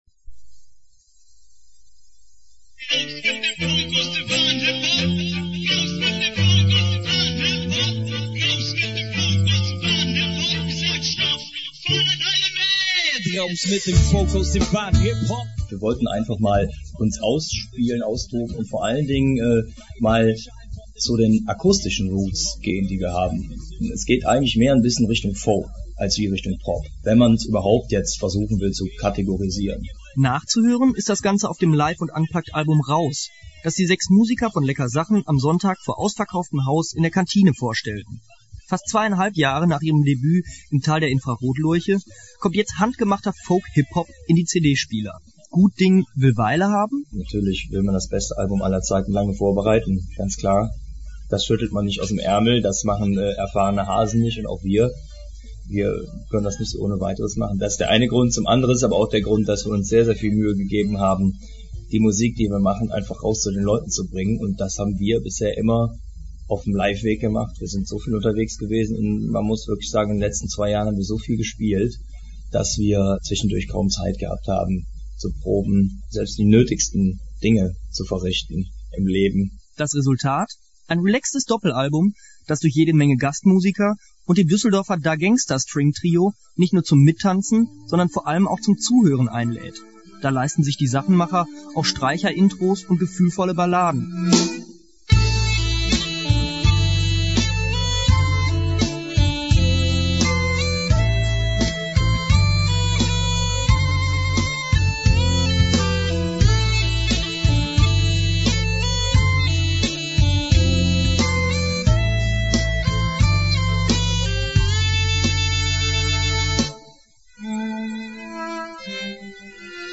Beiträge für WDR Funkhaus Europa
·         Lecker Sachen (Folkhippop aus Deutschland)